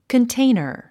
日本でも容器のことをコンテナーって言いますよね。コンテイナァと発音しましょう。